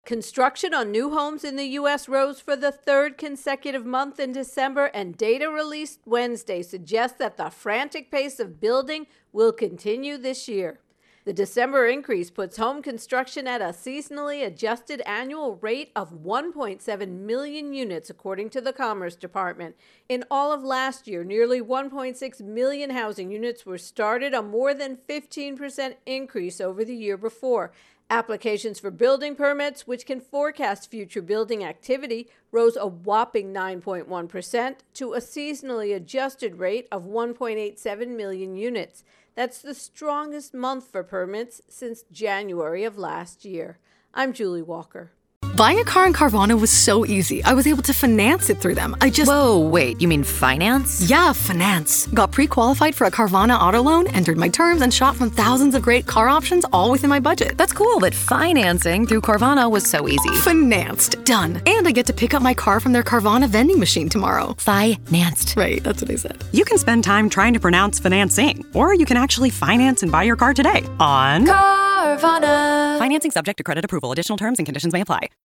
Home Construction intro and voicer